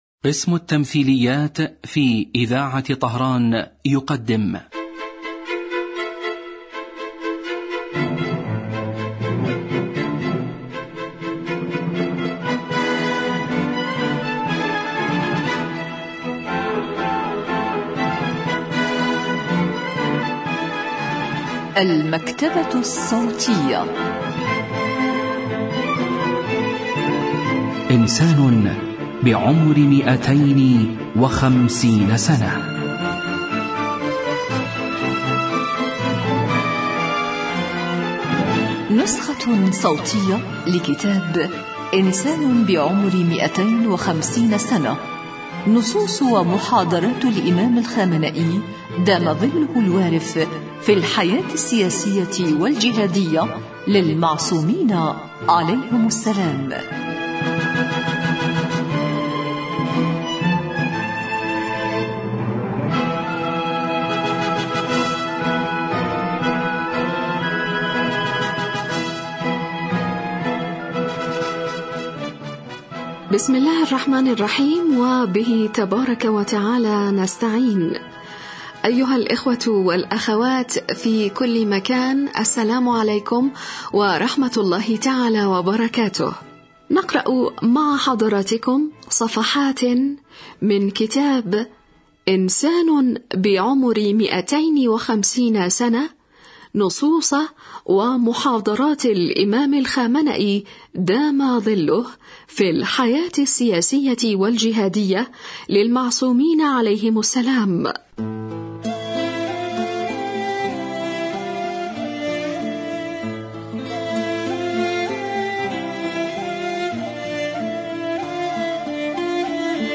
إذاعة طهران- إنسان بعمر 250 سنة: نسخة صوتية لكتاب إنسان بعمر 250 سنة للسيد علي الخامنئي في الحياة السياسية والجهادية للمعصومين عليهم السلام.